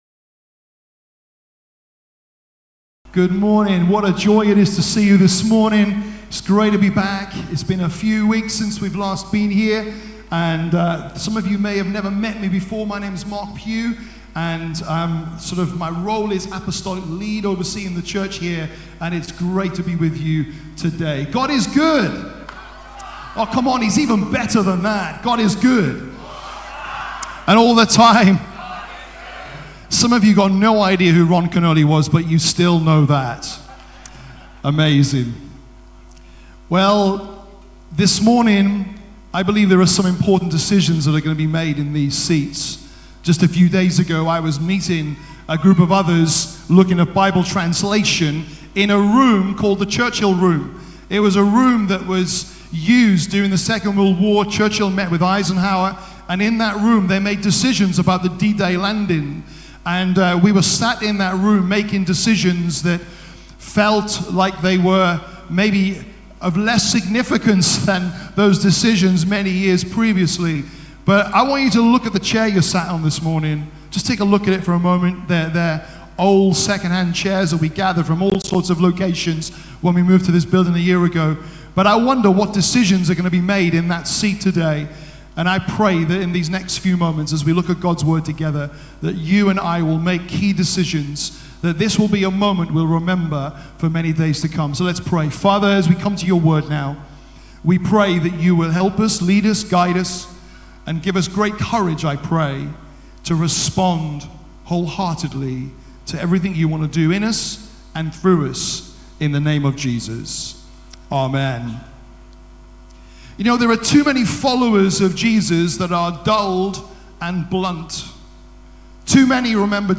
Rediscover Church Exeter | Sunday Messages